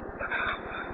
Common EVP Phrases
Are Phrases We Often Hear When Recording EVP